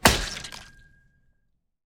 axe_hit.ogg